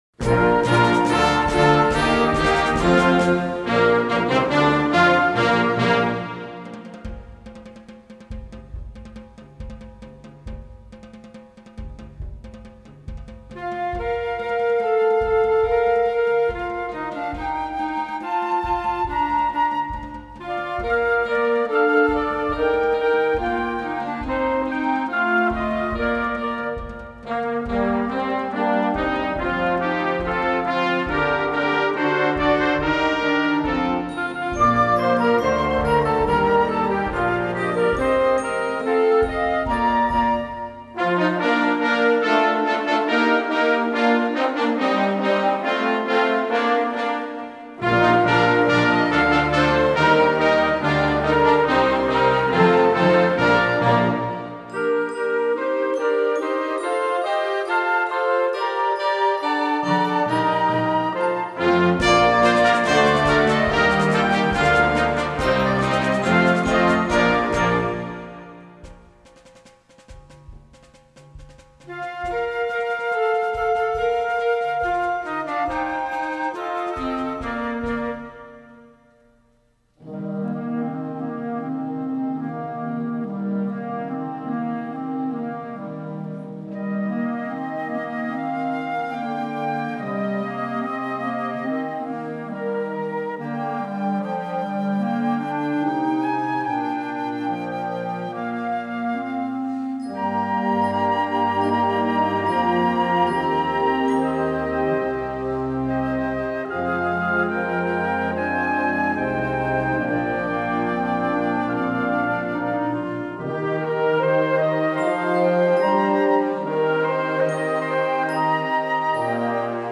輸入吹奏楽オリジナル作品